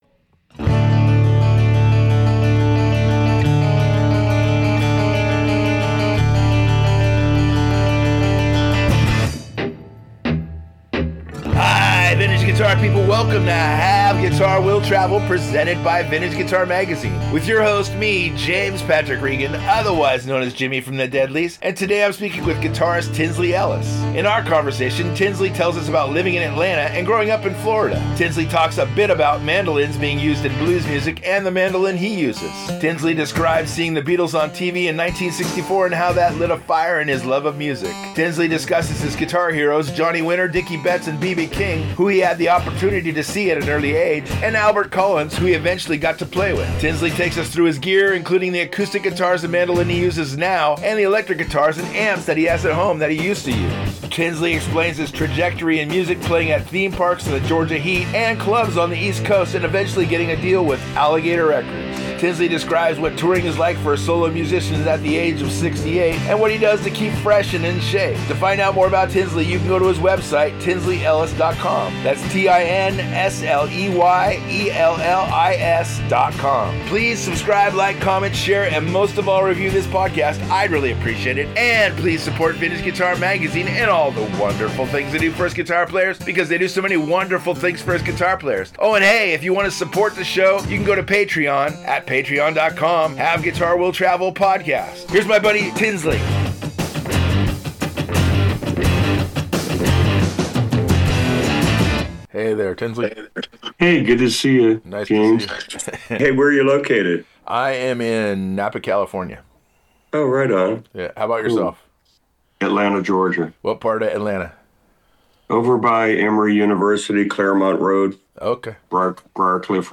speaks with guitarist Tinsley Ellis. In their conversation Tinsley tells us about living in Atlanta and growing up in Florida.